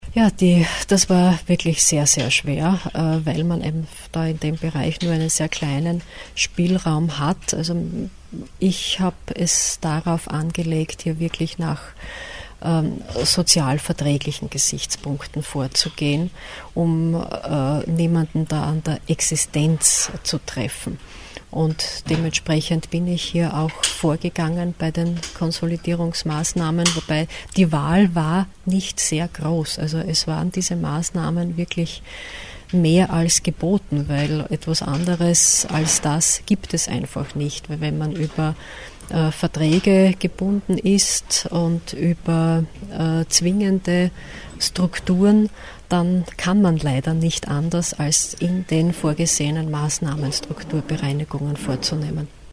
O-Töne Landesrätin Elisabeth Grossmann: